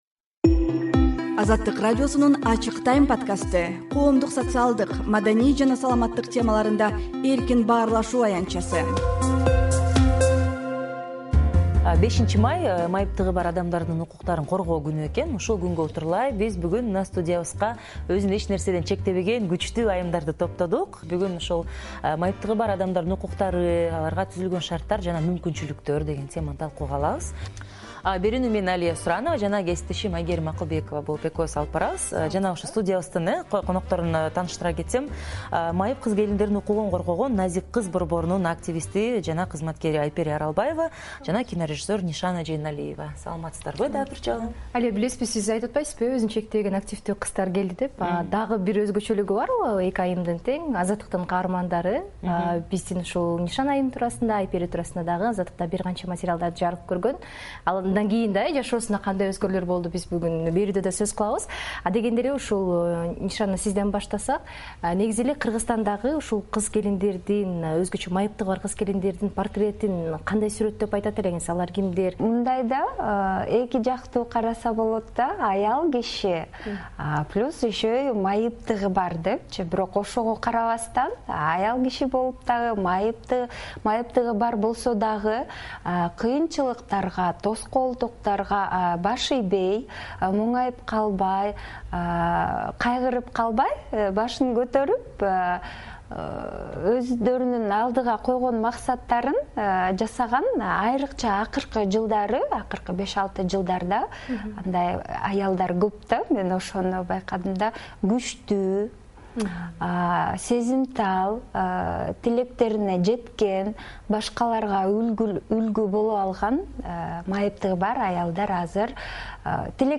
Баарлашуунун аудиосун "Ачык Time" подкастына жарыяладык.